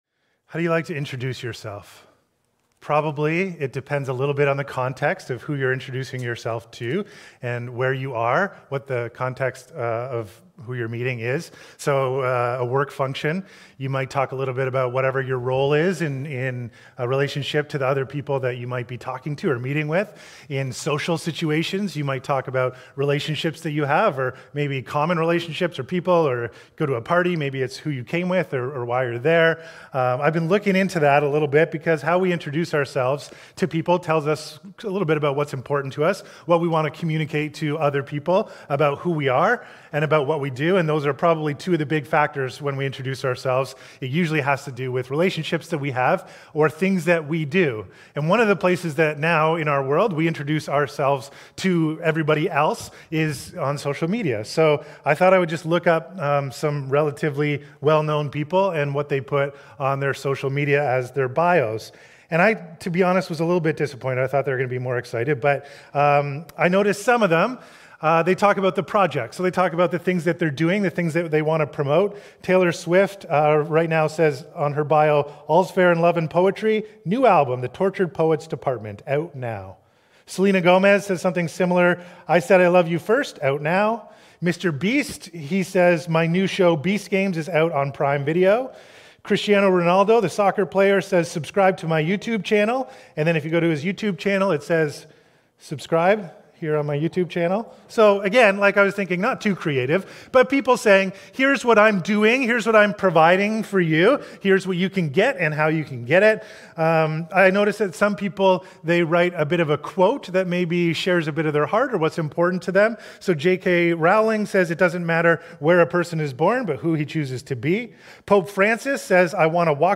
Sermons | Westside Church